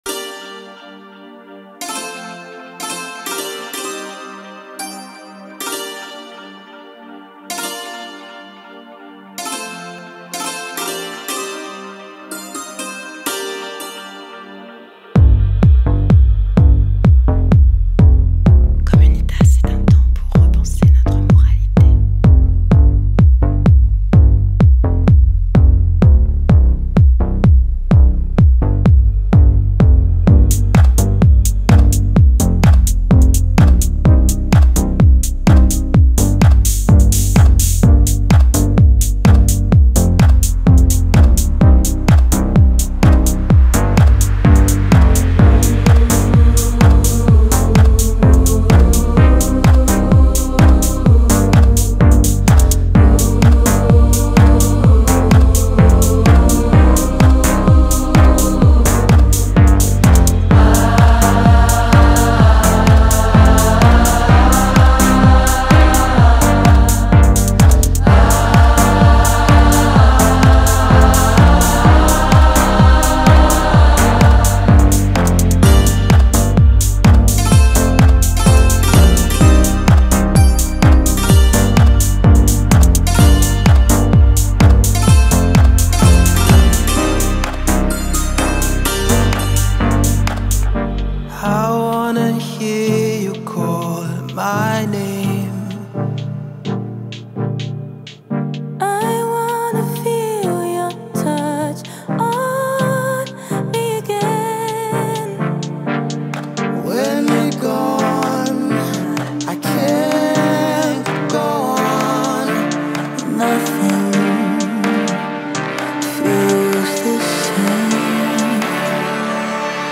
یک گروه موسیقی الکترونیک استرالیایی – نیوزیلندی